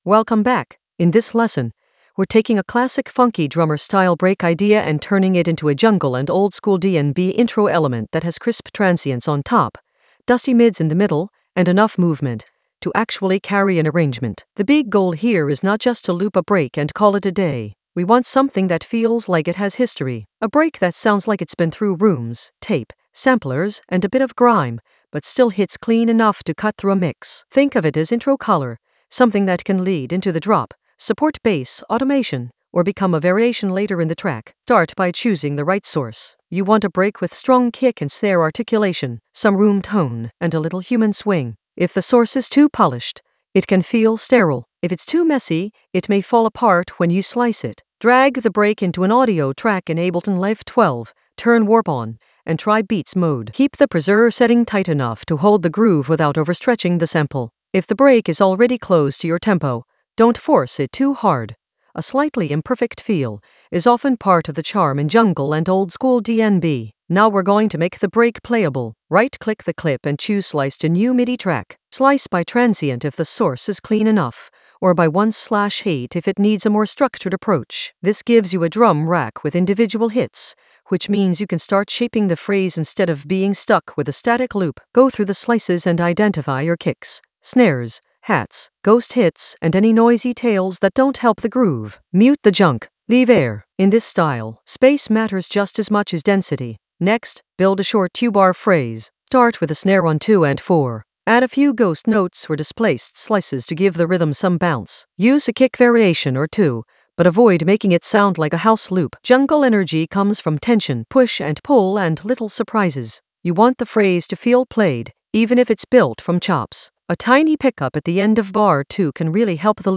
An AI-generated advanced Ableton lesson focused on Funky Drummer: intro color with crisp transients and dusty mids in Ableton Live 12 for jungle oldskool DnB vibes in the Resampling area of drum and bass production.
Narrated lesson audio
The voice track includes the tutorial plus extra teacher commentary.